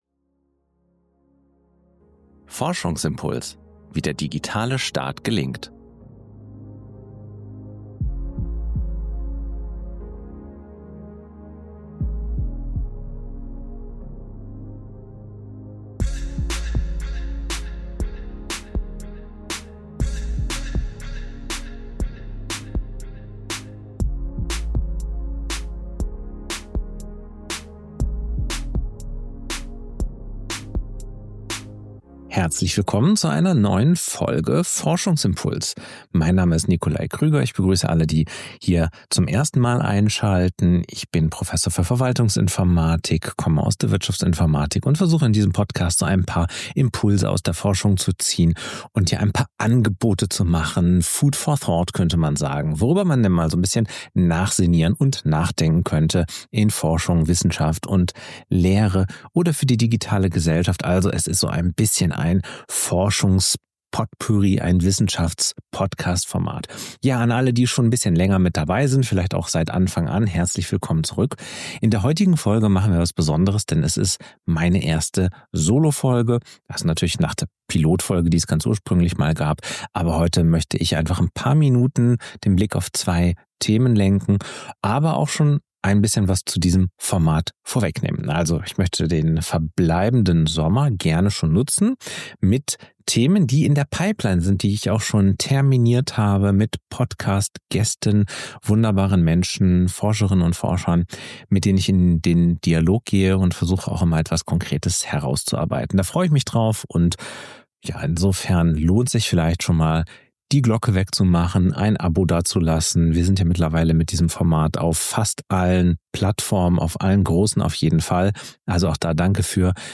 Ich zeige, warum Fallstudien in der Lehre ein Betriebssystem für Denken sein können, was es mit „induktivem Empirismus“ auf sich hat und wie Stadtentwicklung zum Lehrformat wird. Eine Solo-Folge zwischen Governance, Storytelling und Hochschuldidaktik – inspiriert von meinem Besuch in Bilbao.